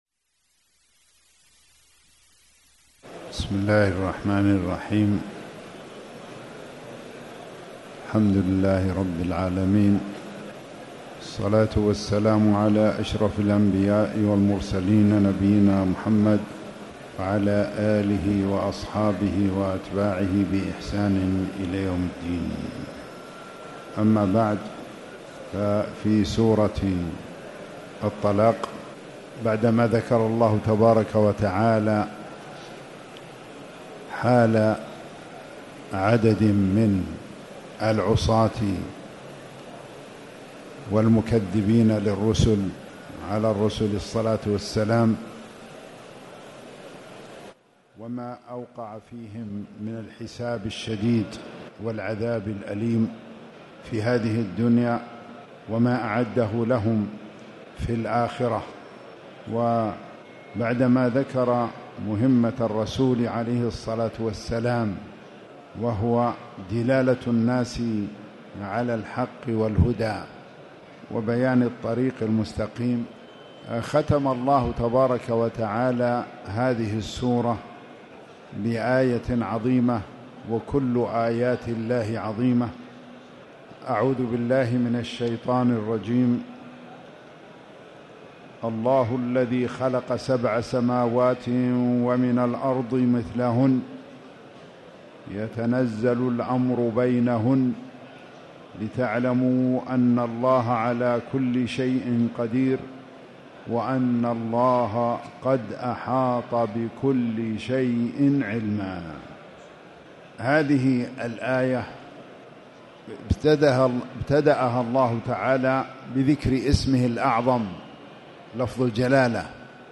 تاريخ النشر ٢٣ رمضان ١٤٣٨ هـ المكان: المسجد الحرام الشيخ